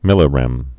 (mĭlə-rĕm)